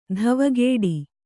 ♪ dhavagēḍi